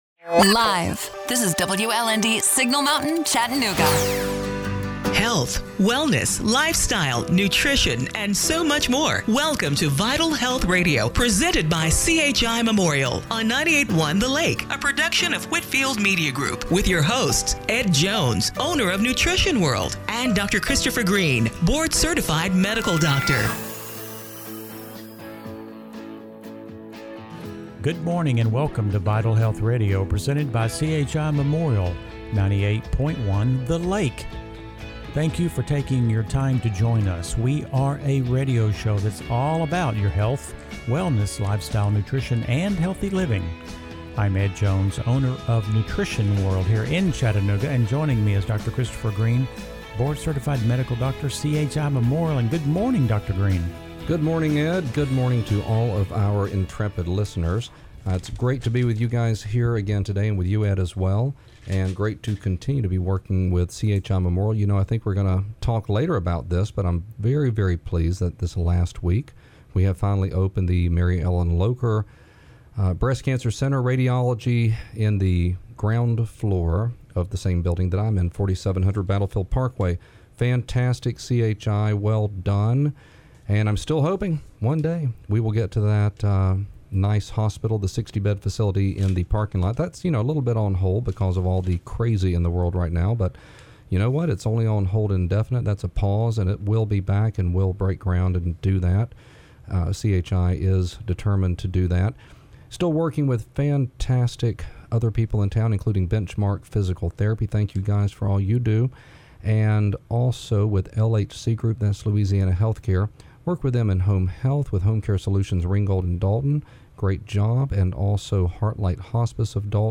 August 9, 2020 – Radio Show - Vital Health Radio